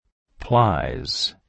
Location: USA
• ''plies" is a heteronym, which means you may find it with different pronunciations and different meanings.